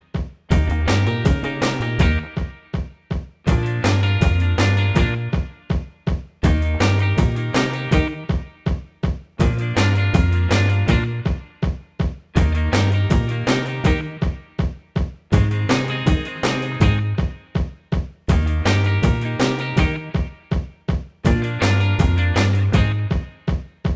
Minus Lead Guitar Pop (1960s) 2:27 Buy £1.50